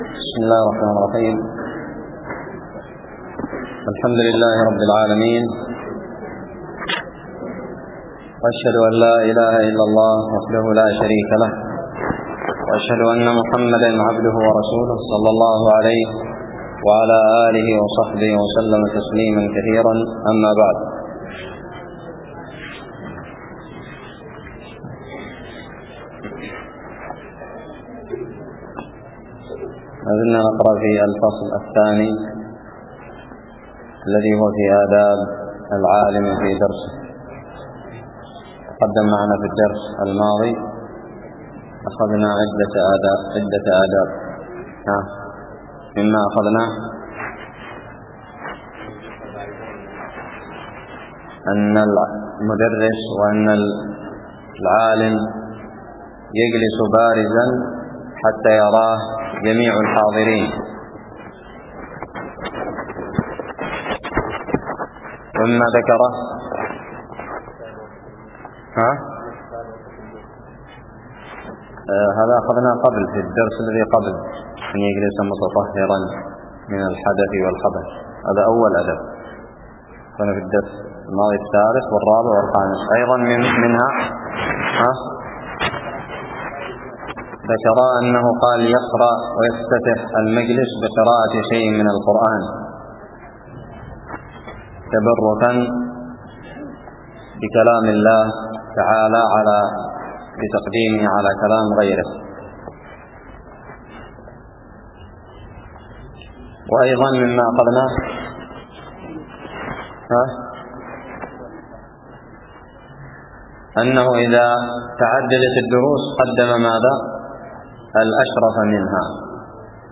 الدرس الرابع والسبعون من كتاب الصلاة من الدراري
ألقيت بدار الحديث السلفية للعلوم الشرعية بالضالع